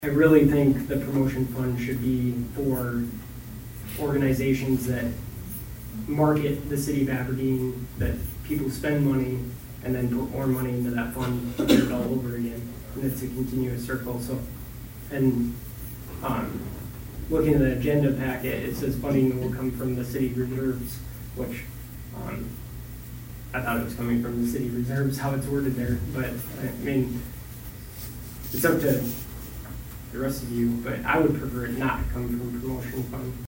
Councilman David Novstrup offered a motion to take the money from the Reserve Fund instead.